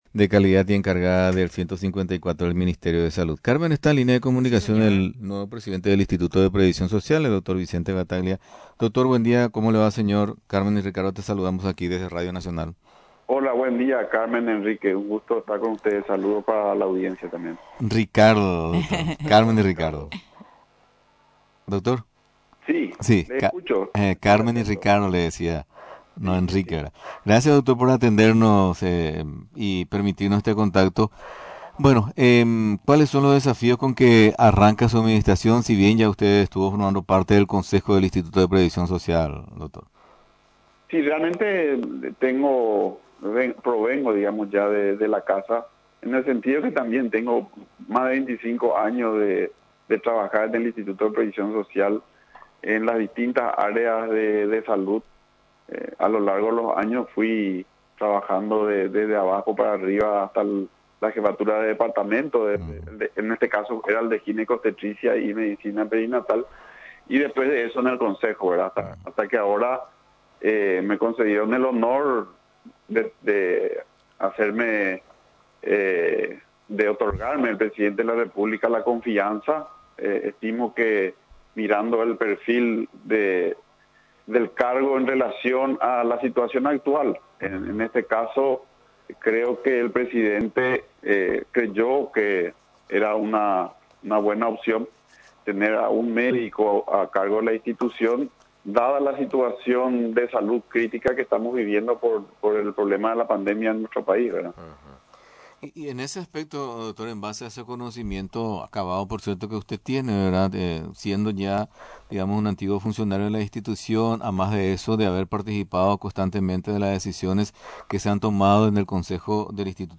El nuevo presidente del Instituto de Previsión Social, Dr. Vicente Bataglia, habló sobre los desafíos del momento en la referida institución.